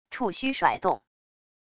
触须甩动wav音频